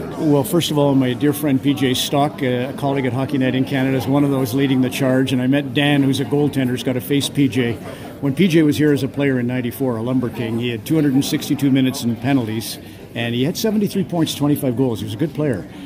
MacLean was at The PMC on the night prior to the Conference signing autographs and telling stories as only he can do and the subject of this Saturday’s “Fall Classic” came up and he had this to say about one of his old cohorts and a guy who will be gracing The PMC with his presence again after many years: